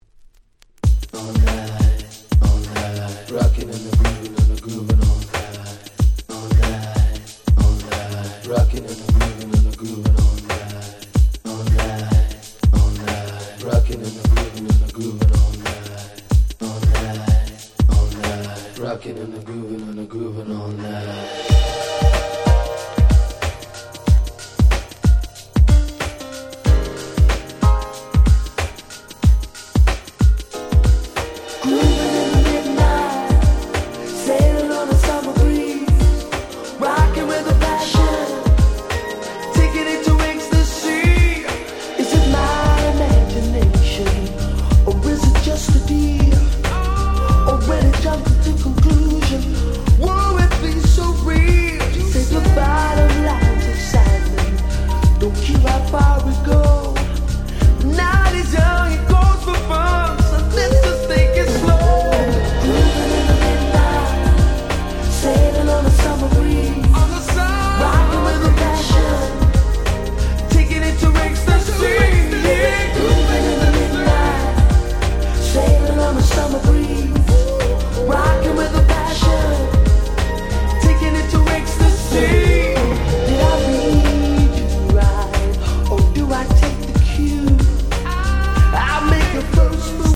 92' Nice Reggae R&B !!
レゲエ